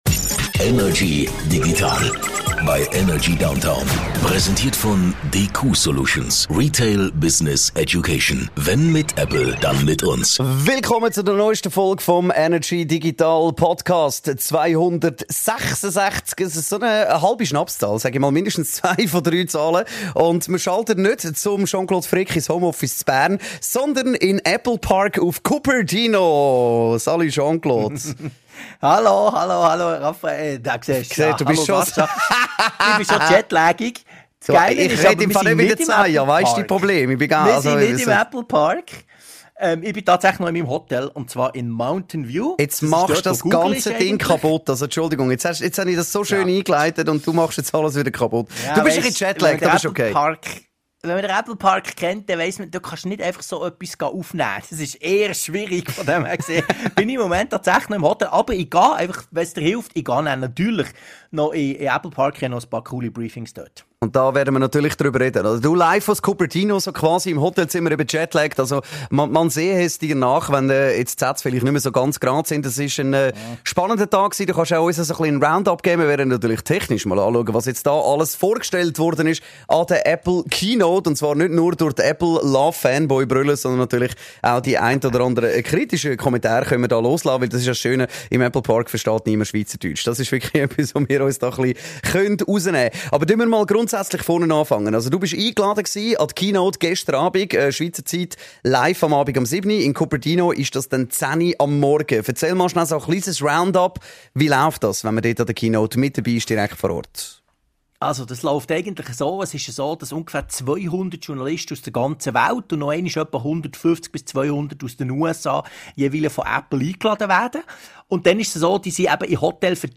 #266 - WIN iPhone 15 - Live aus Cupertino von der Apple Keynote